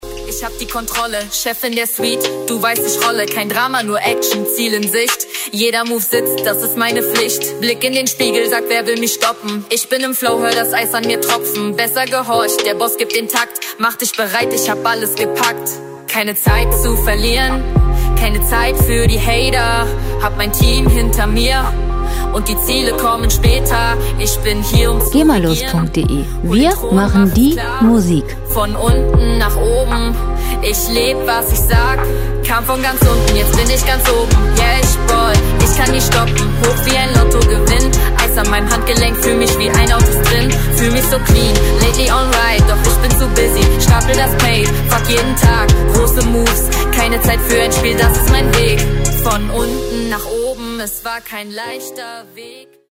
Pop Musik aus der Rubrik: "Popwelt Deutsch"
Musikstil: Deutschrap
Tempo: 140 bpm
Tonart: F-Moll
Charakter: trotzig, frech
Instrumentierung: Piano, Rap, 808 Bass